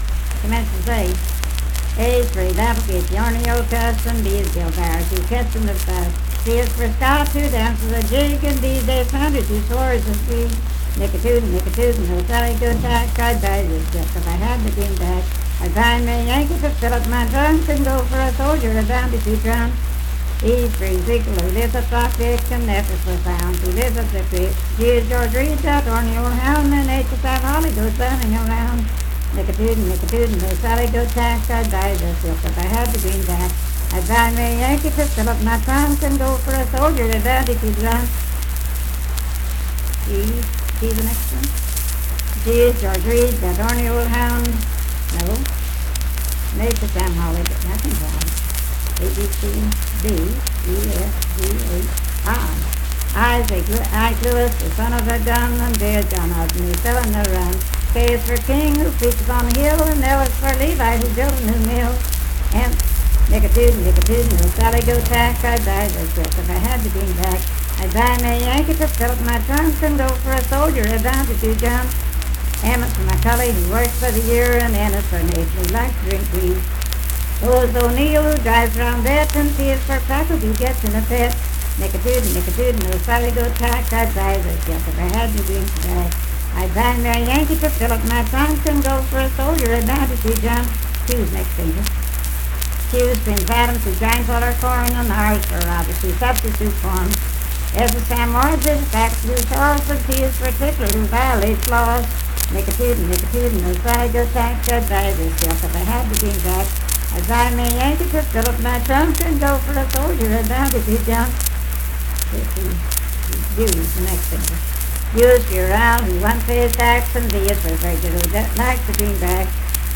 Unaccompanied vocal music performance
Dance, Game, and Party Songs
Voice (sung)
Jackson County (W. Va.)